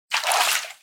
fishcatch.ogg